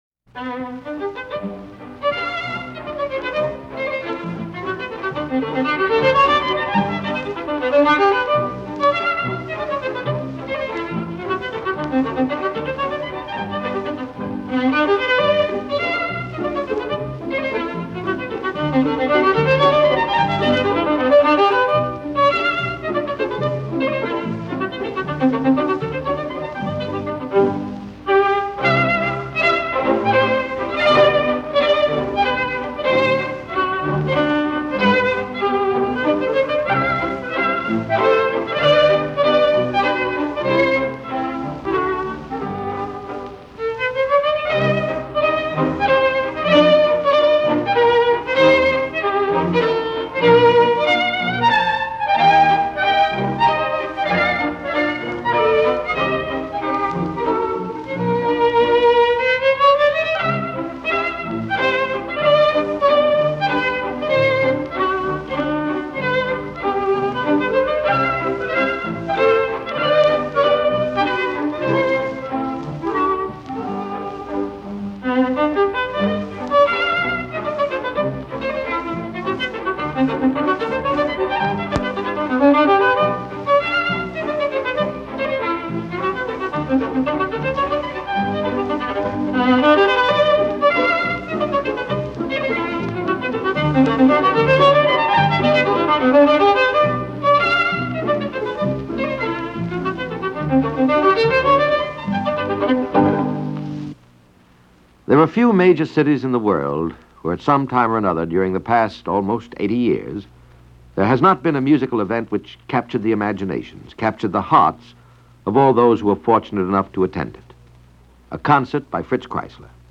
An Interview With Fritz Kreisler - 1955 - Past Daily Weekend Gallimaufry - broadcast on the occasion of Kreisler's 80th birthday.
Fritz-Kreisler-Interview-1955.mp3